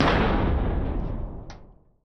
agricultural-tower-rotation-stop.ogg